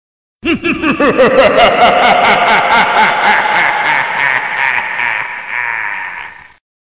MJrwe5uTLjD_halloween-pumpkin-laughing-sound-effec.mp3